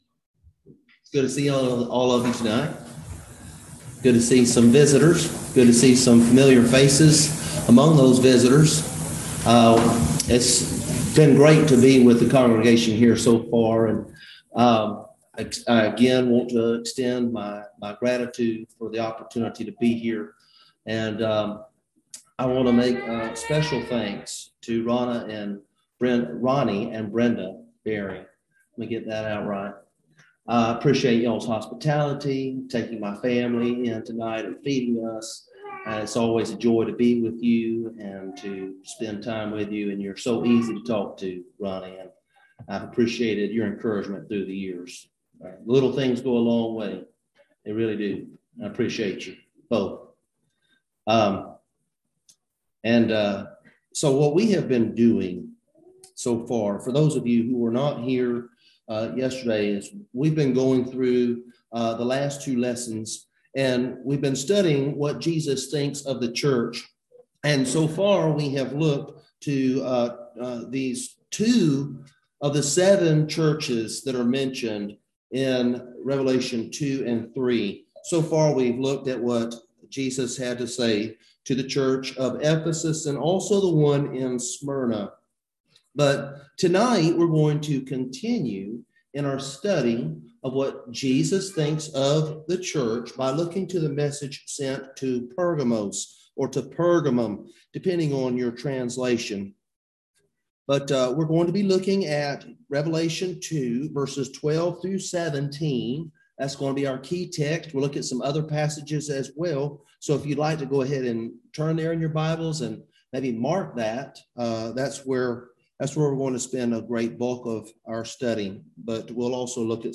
Passage: Revelation 2:12-17 Service Type: Gospel Meeting